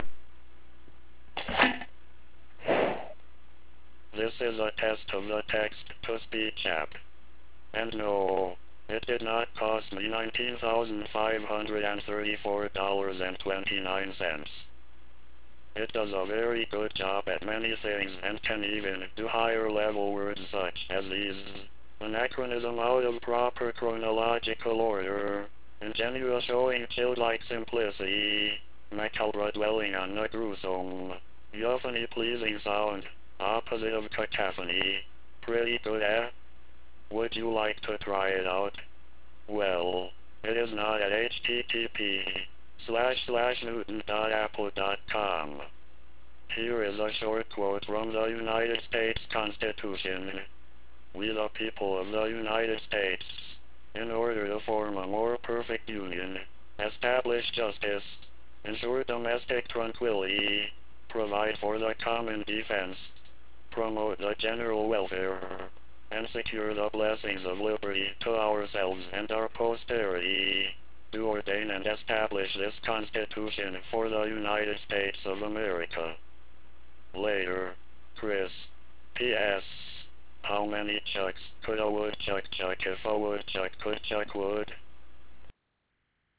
I have recorded the two programs and encoded it in real audio 2.0 for 28.8 modems!
TalkToMe has only one voice, and here it is.